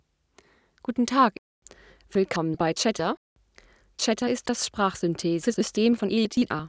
CHATR's German synthesis)